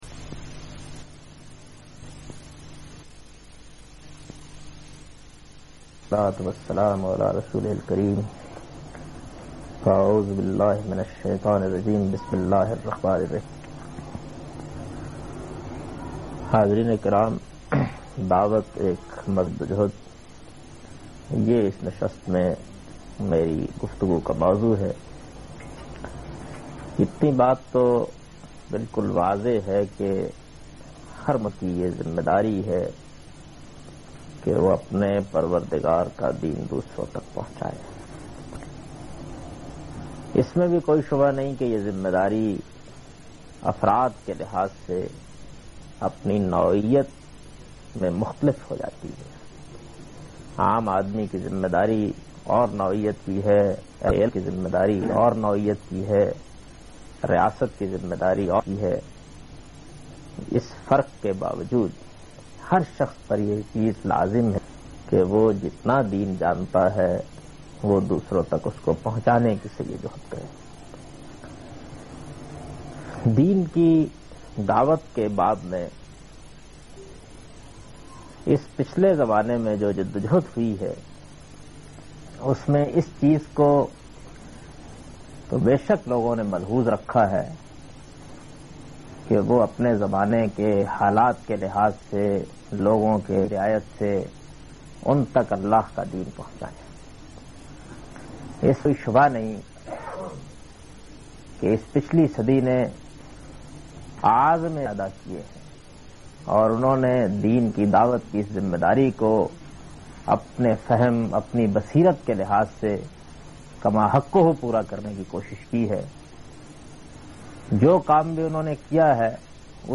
Lecture by Javed Ahmad Ghamidi on the topic-Dawat aik Mazlumana Jadojehad